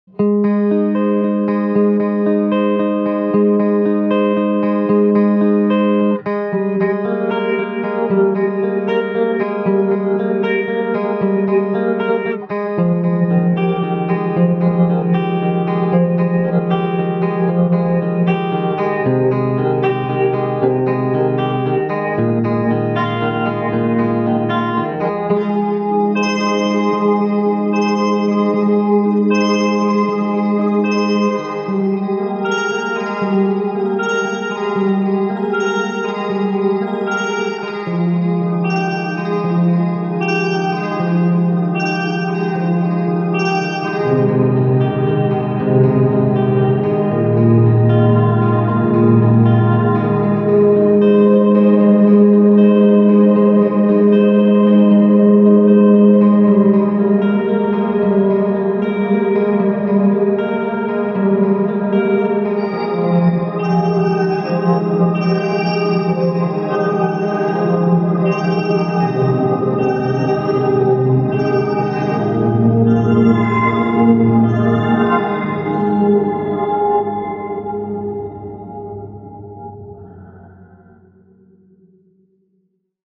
Lair can get you some sound effects free download By aberrantdsp 0 Downloads 10 months ago 83 seconds aberrantdsp Sound Effects About Lair can get you some Mp3 Sound Effect Lair can get you some pretty weird sounds, but you can get even weirder by stacking them together.